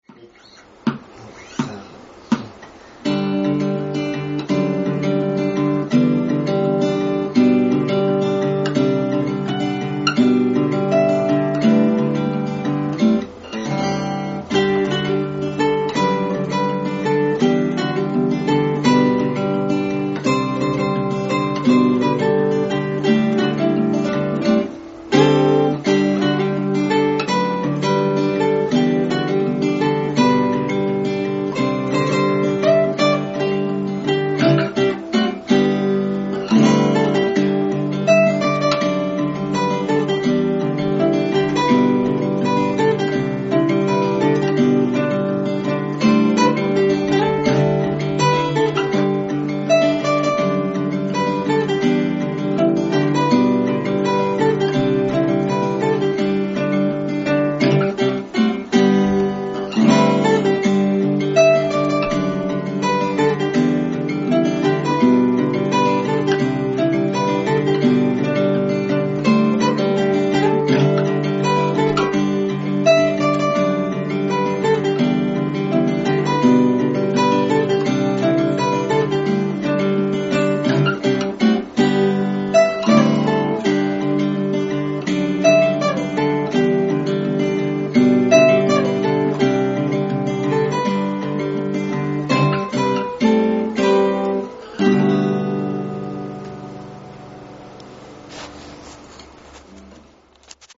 アコースティックギターインスト ゼロゼロヘブン 試聴あり ライブ告知
本日は、カバー曲を中心に練習した。
もう少し精度を上げないと原曲に失礼になるなぁ・・・
リードギター